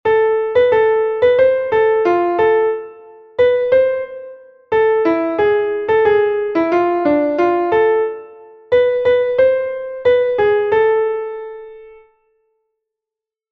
Entoación a capella
Melodía en 2/8 en La m